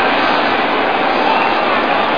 CROWDL.mp3